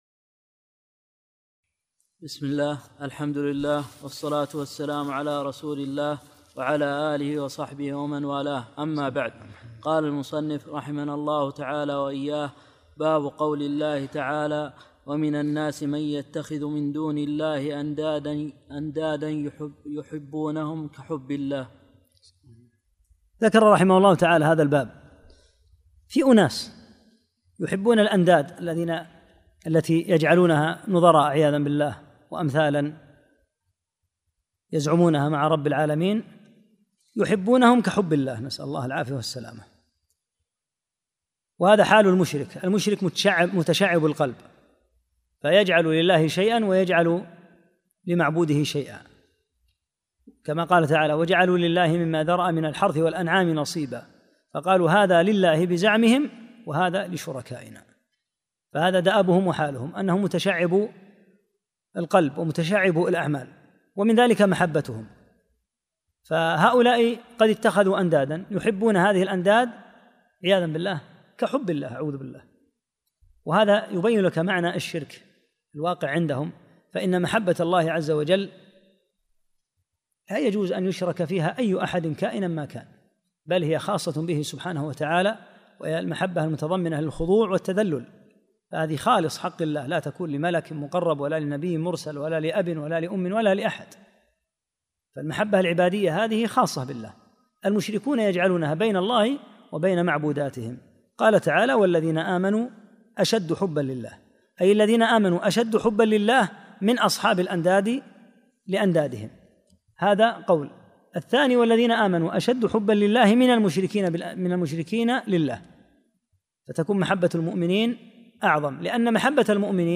30- الدرس الثلاثون